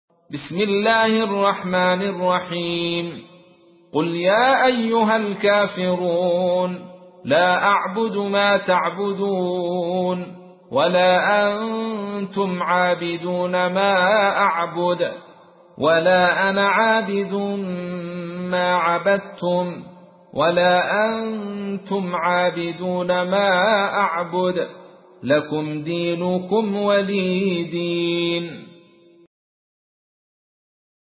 تحميل : 109. سورة الكافرون / القارئ عبد الرشيد صوفي / القرآن الكريم / موقع يا حسين